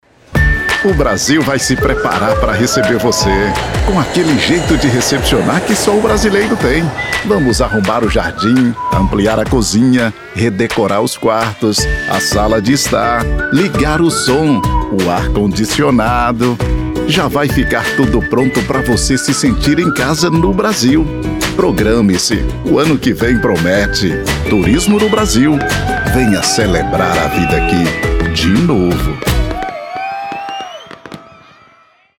Explore commercial voice talent for radio and TV ads.
It would be warm, natural, informative or serious, authoritative.